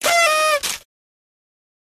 confetti.ogg